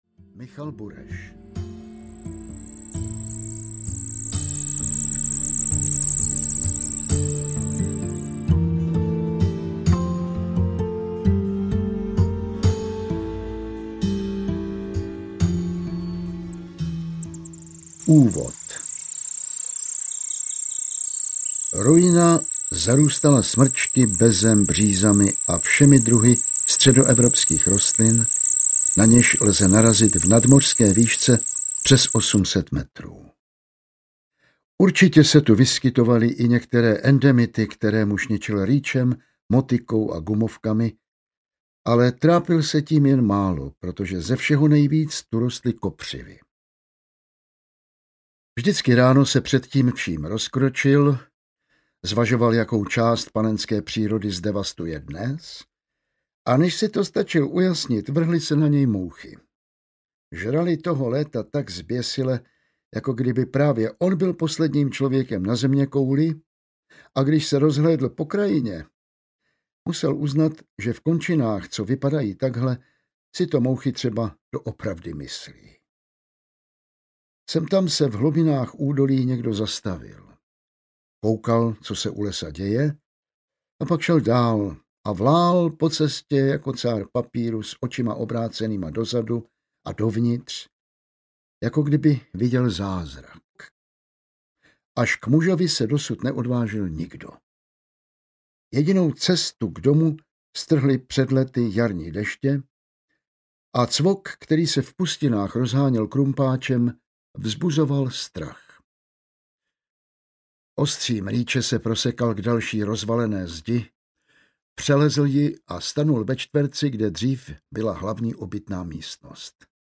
Cejch audiokniha
Ukázka z knihy
• InterpretJan Vlasák